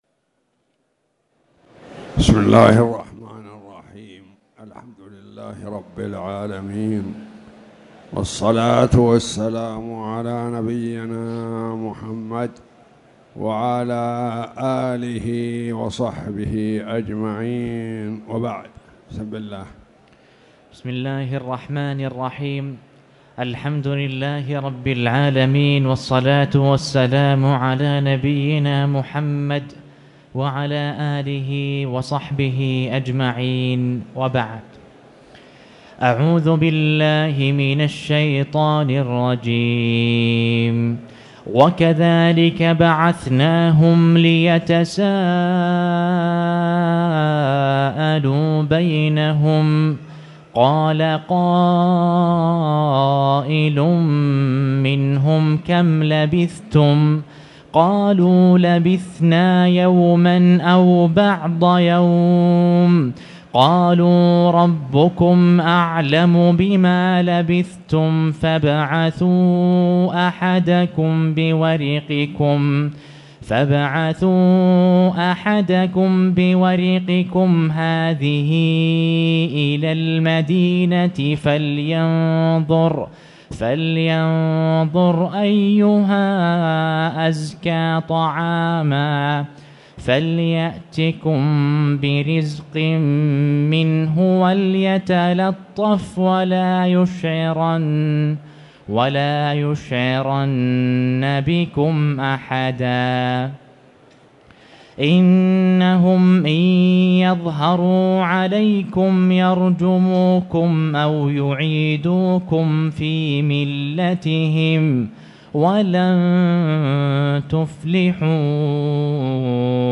تاريخ النشر ٢٣ جمادى الآخرة ١٤٣٨ هـ المكان: المسجد الحرام الشيخ